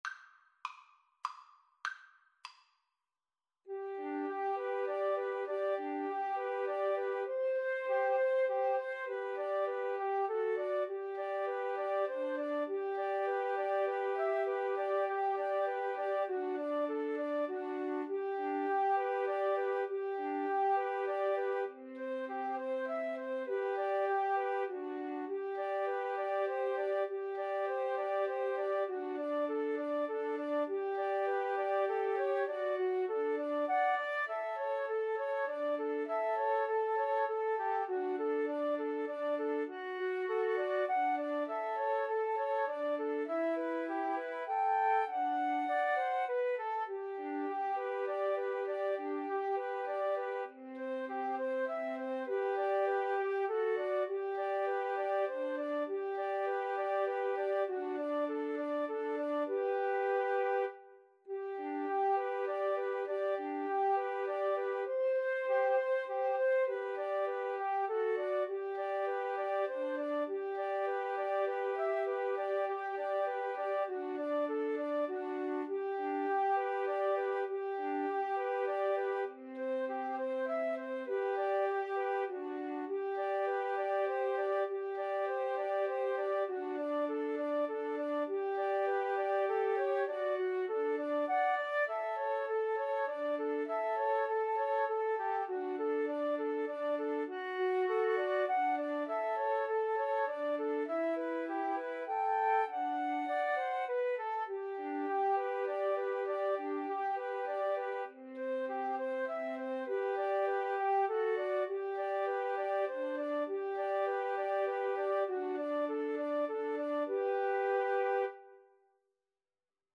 G major (Sounding Pitch) (View more G major Music for Flute Trio )
Moderato
3/4 (View more 3/4 Music)
Flute Trio  (View more Easy Flute Trio Music)
Classical (View more Classical Flute Trio Music)